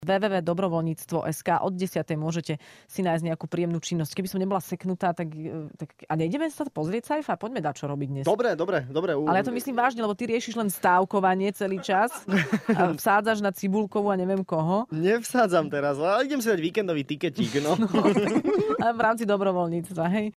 Vypočujte si to najlepšie z Rannej šou s Adelou a Sajfom!